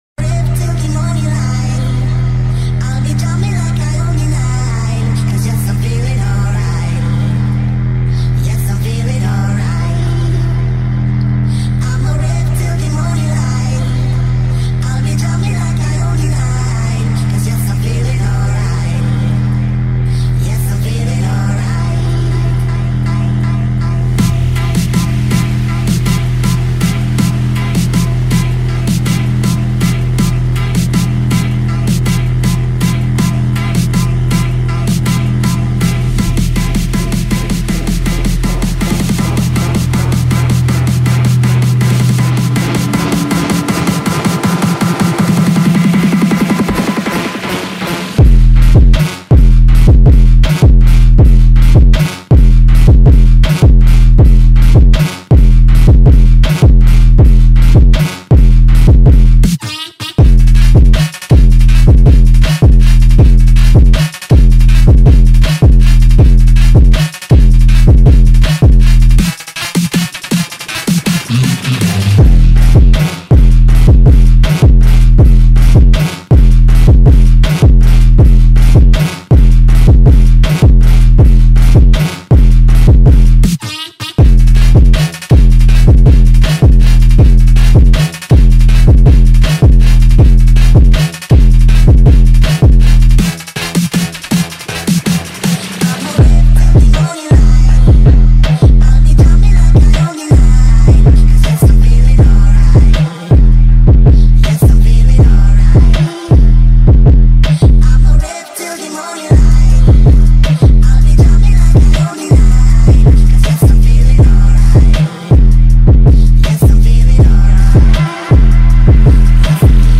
TEST - EXTREME BASS TEST 1.mp3